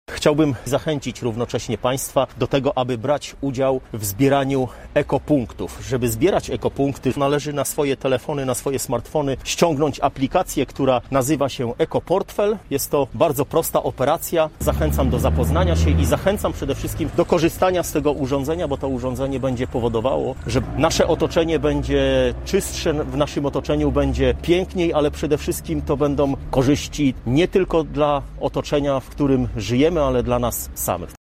– Konieczne jest ściągnięcie na swój smartfon specjalnej aplikacji – mówi Mariusz Olejniczak, burmistrz Słubic: